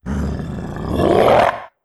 RiftMayhem / Assets / 1-Packs / Audio / Monster Roars / 10.
10. Attack Roar.wav